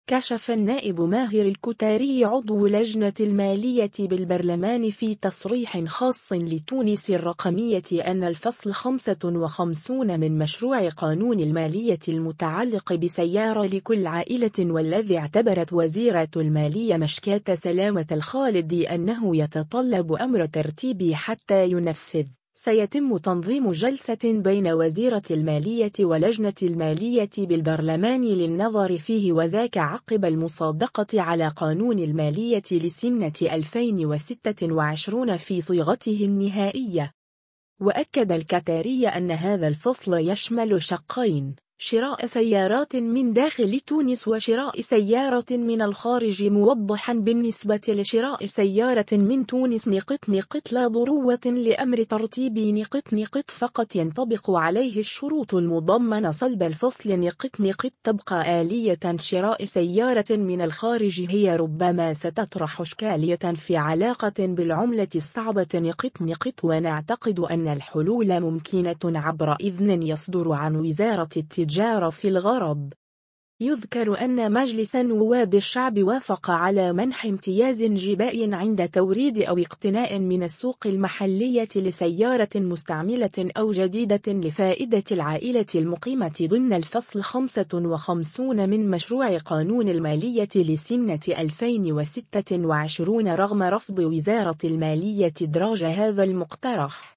كشف النائب ماهر الكتاري عضو لجنة المالية بالبرلمان في تصريح خاص لـ”تونس الرقمية” أن الفصل 55 من مشروع قانون المالية المتعلق بـ “سيارة لكل عائلة” والذي اعتبرت وزيرة المالية مشكاة سلامة الخالدي أنه يتطلب أمر ترتيبي حتى ينفذ،سيتم تنظيم جلسة بين وزيرة المالية ولجنة المالية بالبرلمان للنظر فيه وذاك عقب المصادقة على قانون المالية لسنة 2026 في صيغته النهائية.